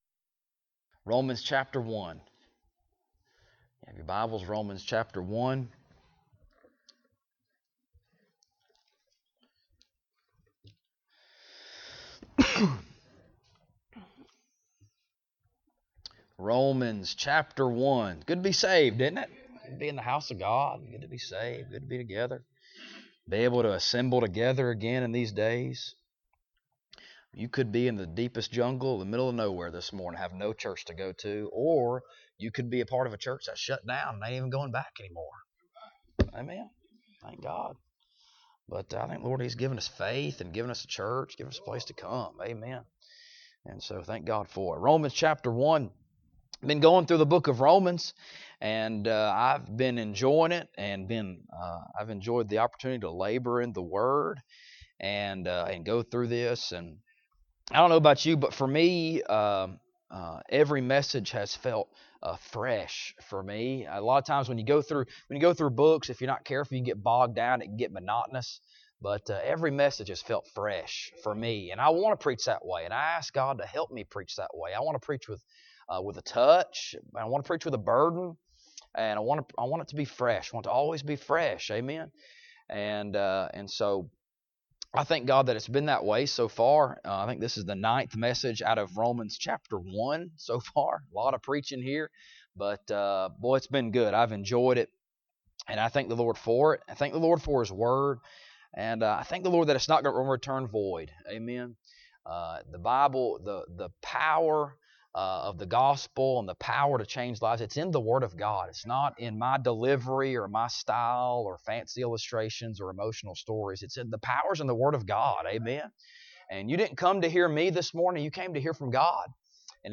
Romans Passage: Romans 1:18-32 Service Type: Sunday Morning Topics